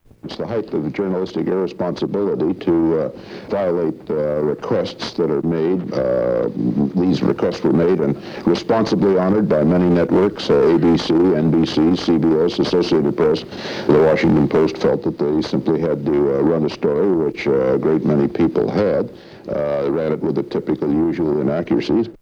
U.S. Secretary of Defense Caspar Weinberger accuses the Washington Post of journalistic irresponsibility